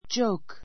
joke A2 dʒóuk ヂョ ウ ク 名詞 冗談 じょうだん , しゃれ tell [make] a joke tell [make] a joke 冗談を言う as a joke as a joke 冗談（のつもり）で It's no joke.